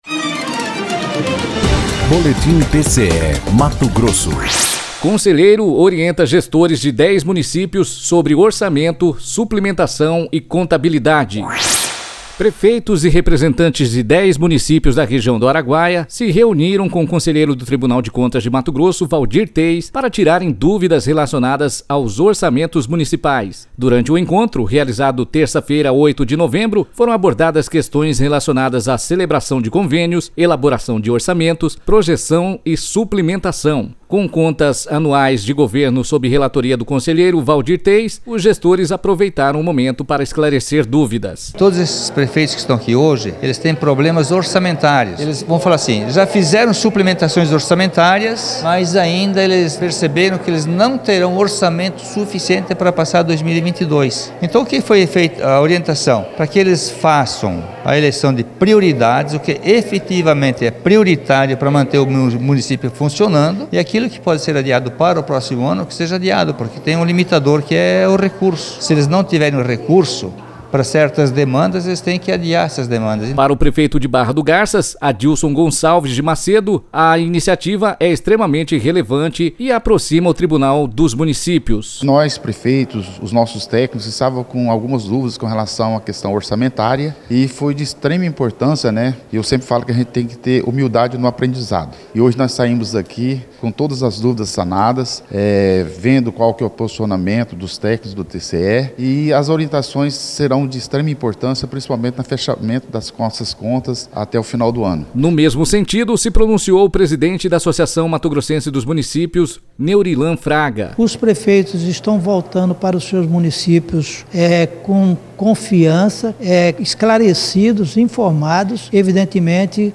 Sonora: Waldir Teis – conselheiro supervisor da Escola Superior de Contas
Sonora: Adilson Gonçalves de Macedo - prefeito de Barra do Garças
Sonora: Max Russi – deputado por MT